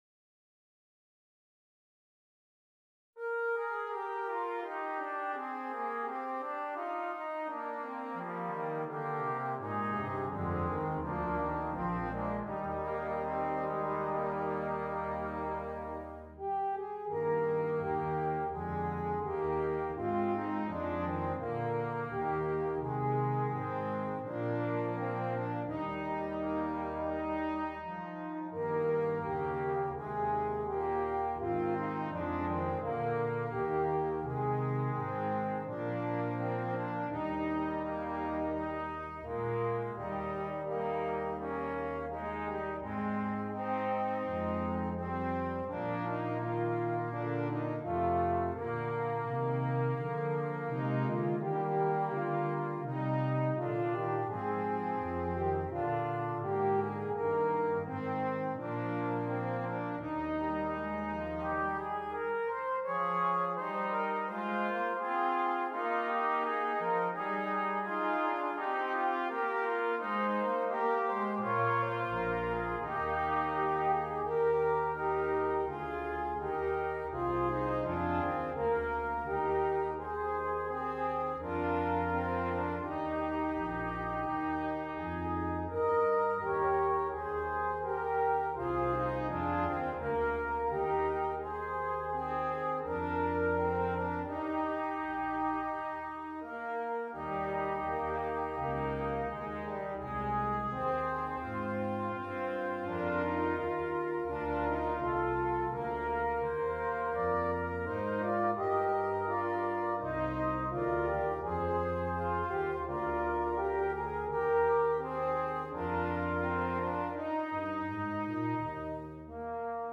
Brass Quintet
Traditional